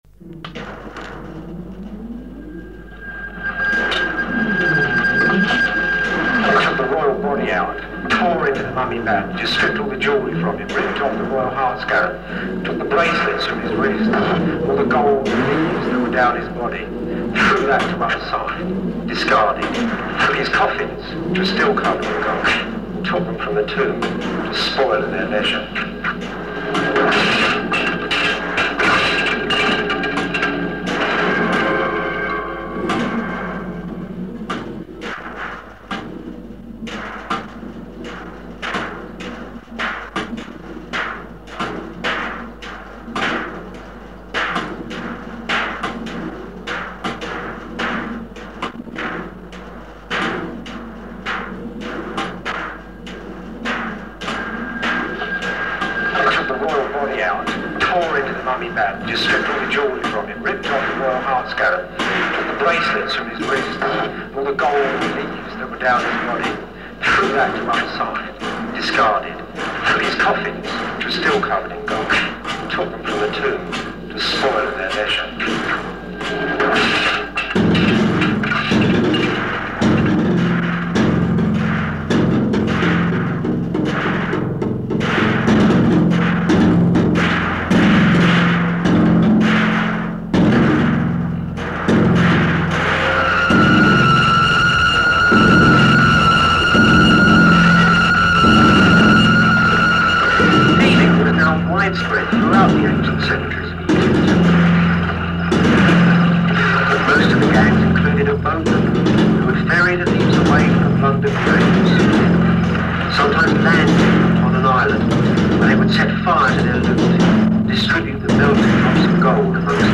Industrial-Klassiker
Die rauhe klangliche Textur, die metallische Stimme…